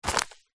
На этой странице собраны натуральные звуки семечек: от раскалывания скорлупы зубами до шуршания шелухи.
Упаковку взяли со стола